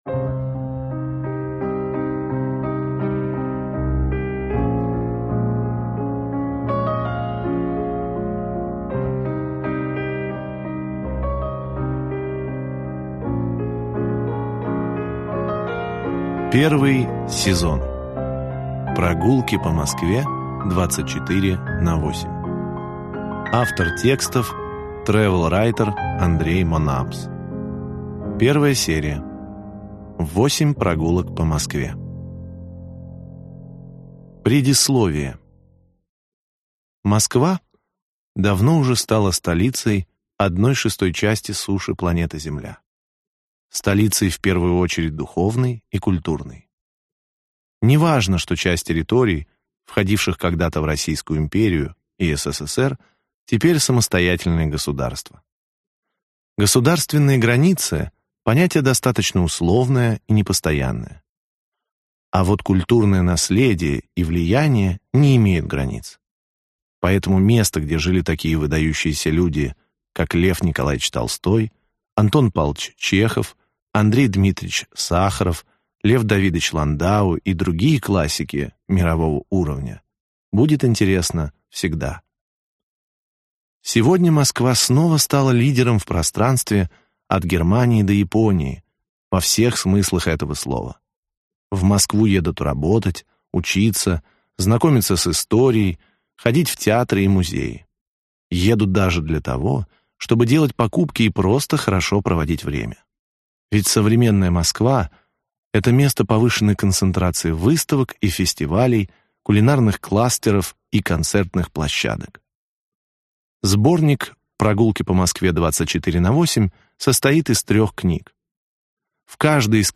Аудиокнига 8 прогулок по Москве. Путеводитель | Библиотека аудиокниг